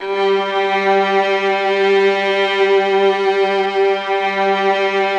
MELLOTRON ST.wav